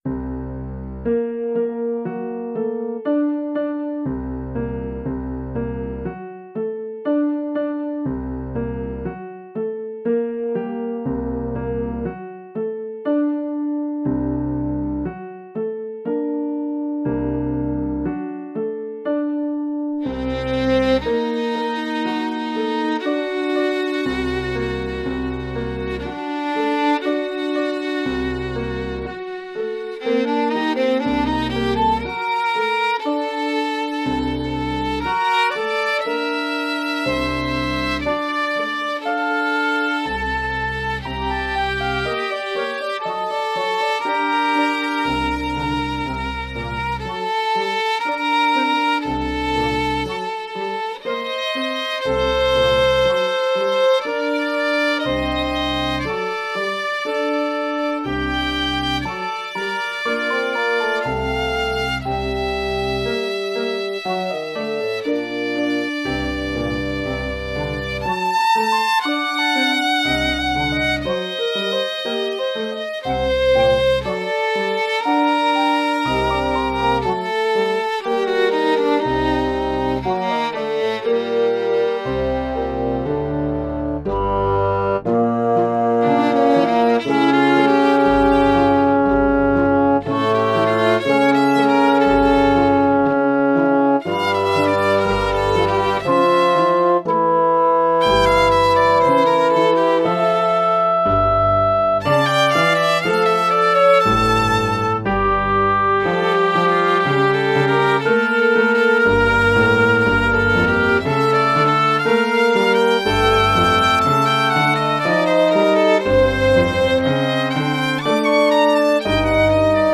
Babash: 4 pieces for Violin and Chamber Orchestra